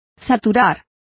Complete with pronunciation of the translation of saturates.